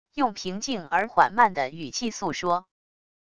用平静而缓慢的语气诉说wav音频